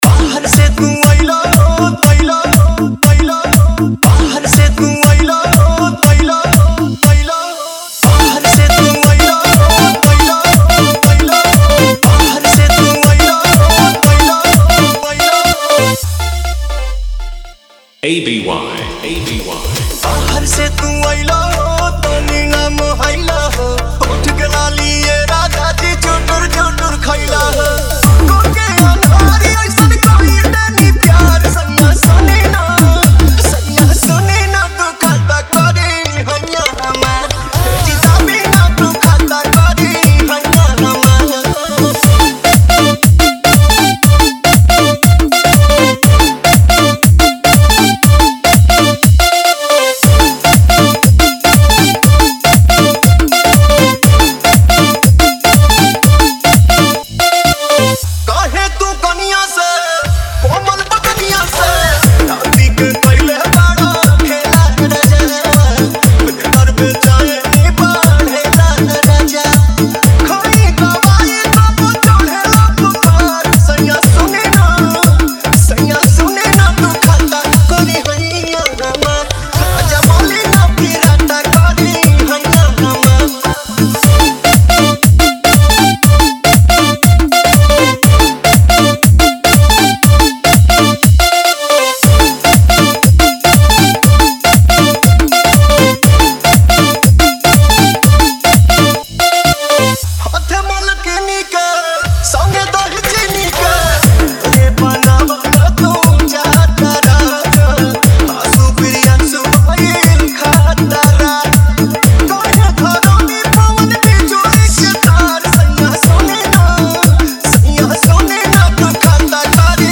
DJ Dialogue Intro Script – UP 70 Bass Style
[Start with हल्की सीटियाँ + Haryanvi-style flute + bass hum]
[Drop starts: 808 heavy roll + vocal cut]